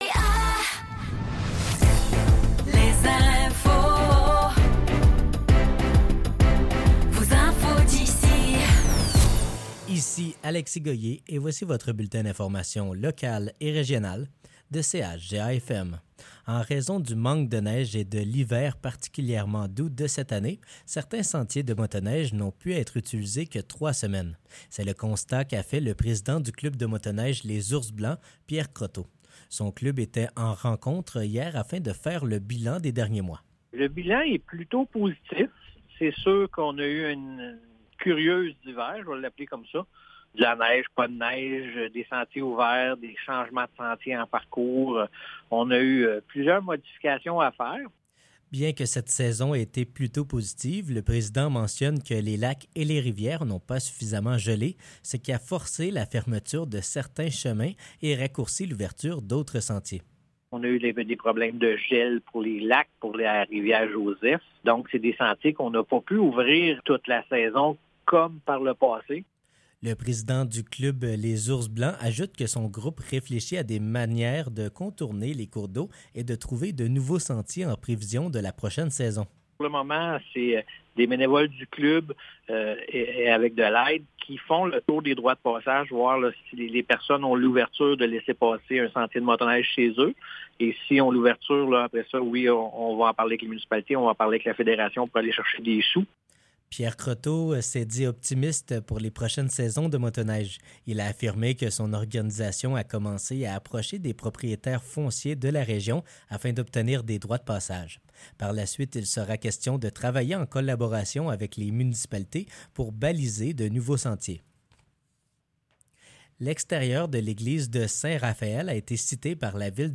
Nouvelles locales - 12 mars 2024 - 15 h